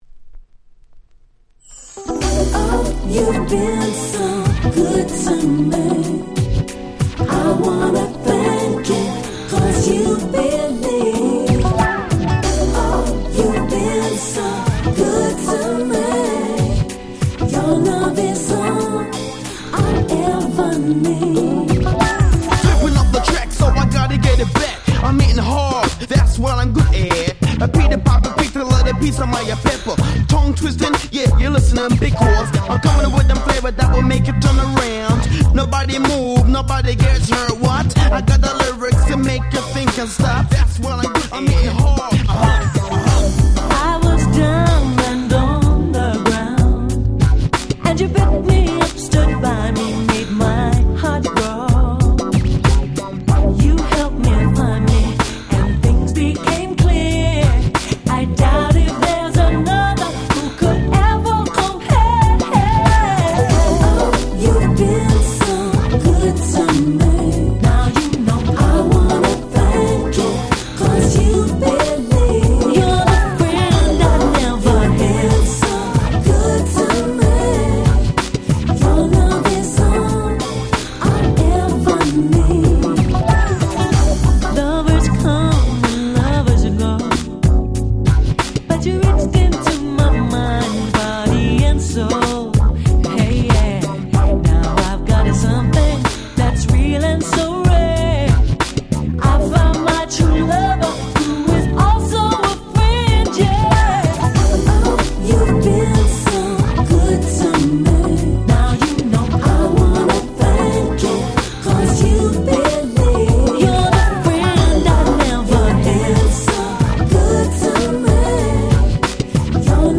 97' Super Nice UK R&B !!